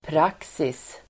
Uttal: [pr'ak:sis]